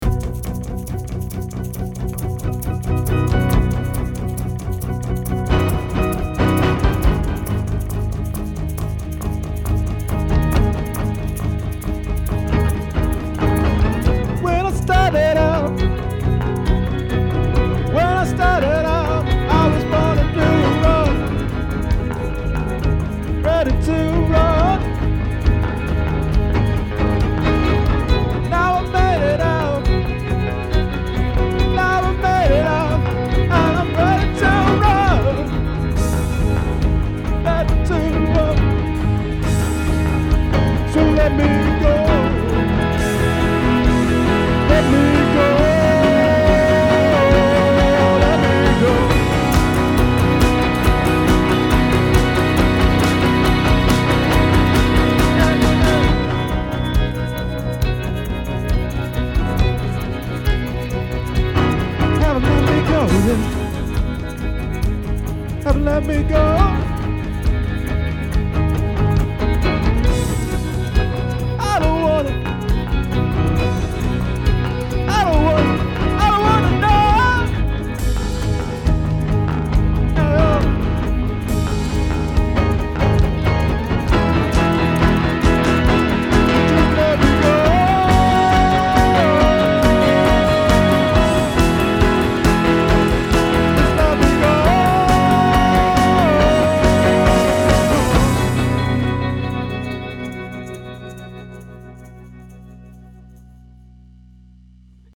Tried to channel my inner Arcade Fire today: Jan 18.mp3